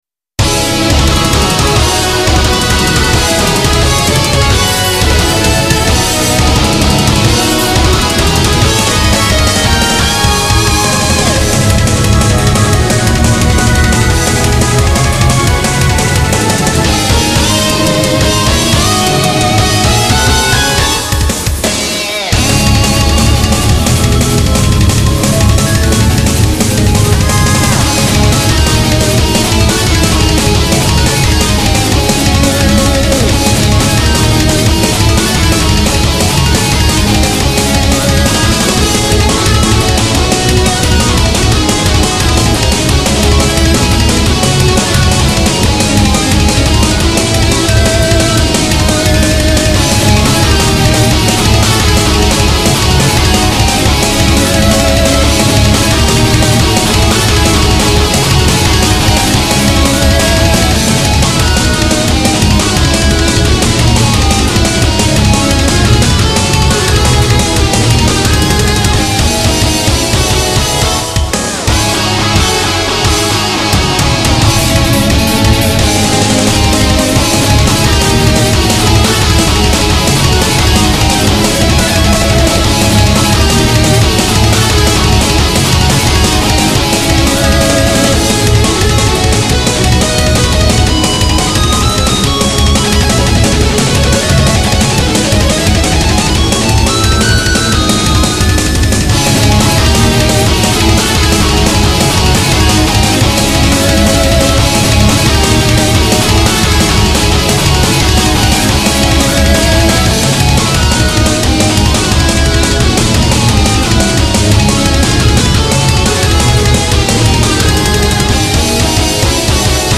YAMAHA MU2000を使ってゲームBGMのメタルアレンジをしています